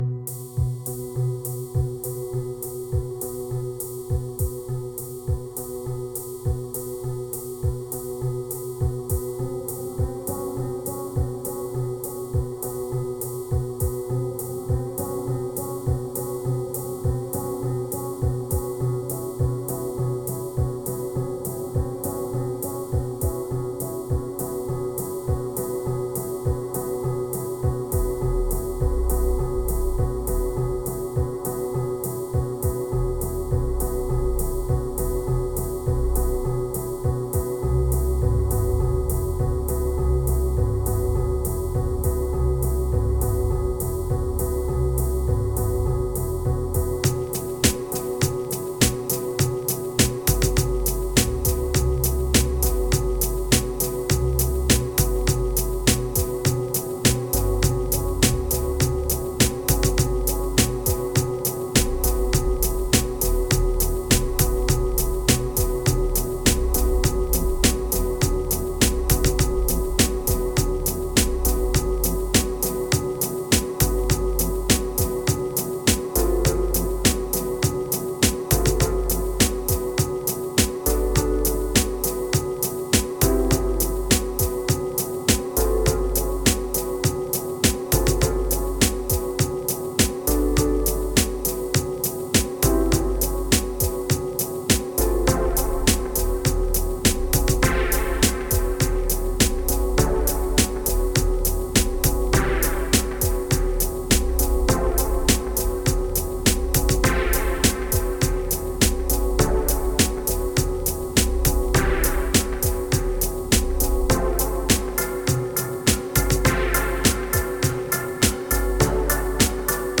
Prepare your subwoofer or your deep headz.
Ambient Kicks Moods Spring Rejoice Times Instant Bliss Hope